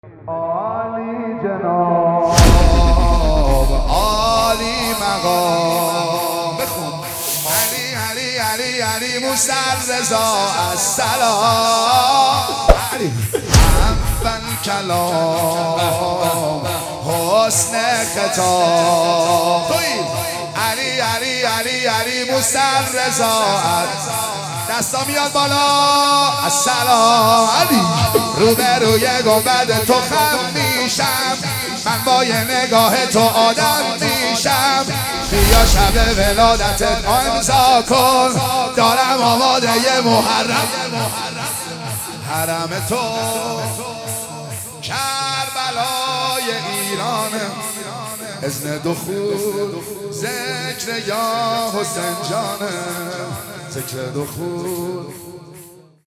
هیئت مکتب البکا مشهد